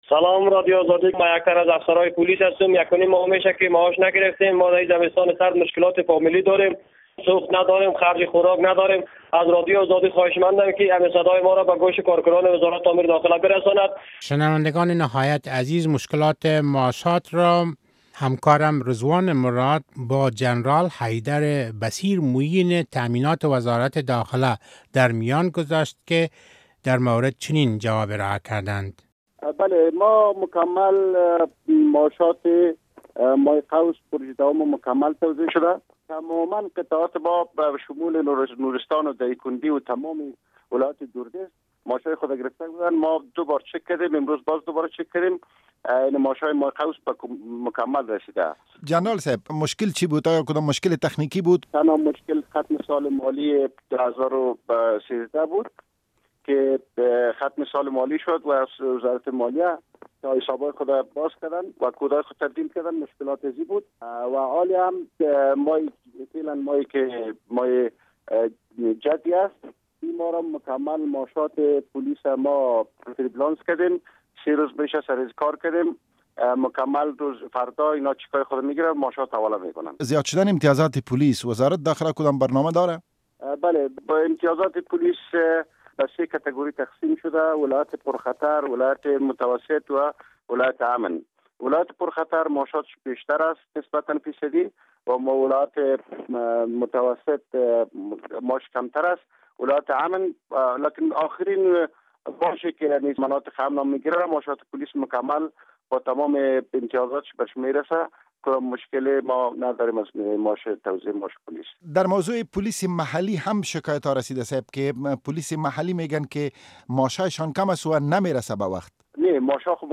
مصاحبه با جنرال حیدر بصیر در مورد عدم پرداخت معاشات پولیس